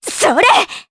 Juno-Vox_Attack3_jp.wav